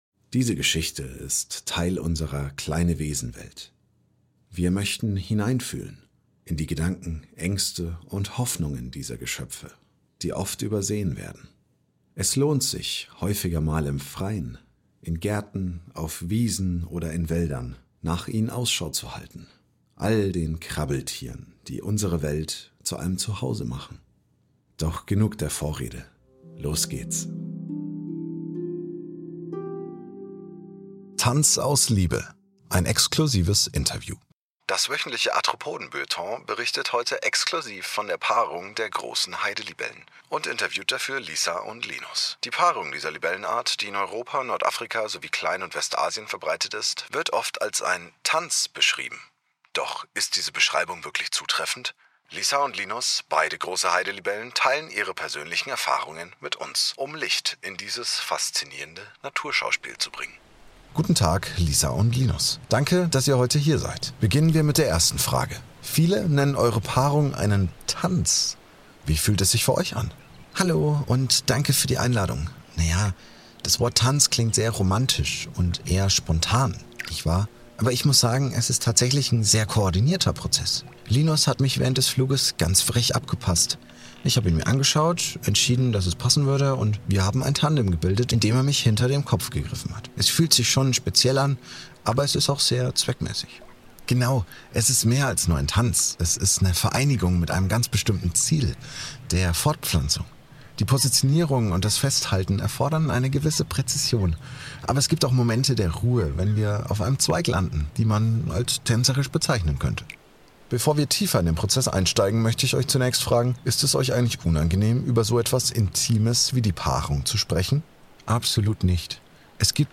Interview mit Heidelibellen Lisa und Linus über ihren Paarungstanz: Koordinierter Ablauf statt Romantik. Offene Worte über Partnerwahl und Fortpflanzung.
Ein Interview, das kaum persönlicher sein kann.